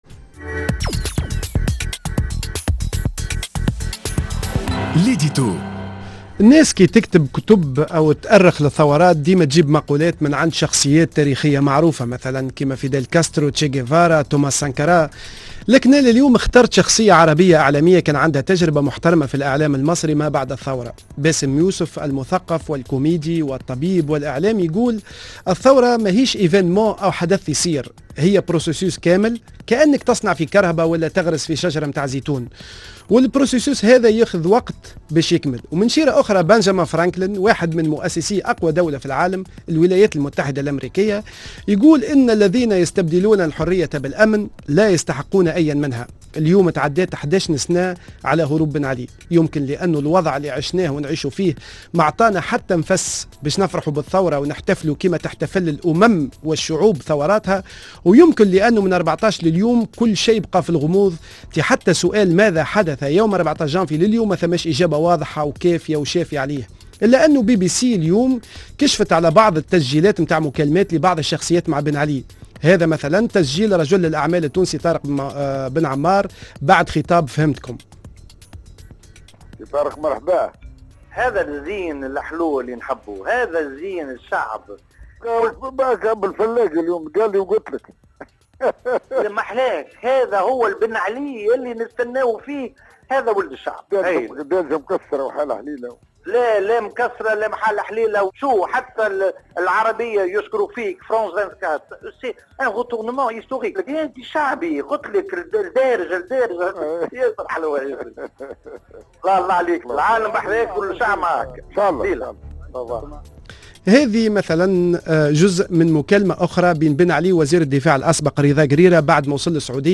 L'édito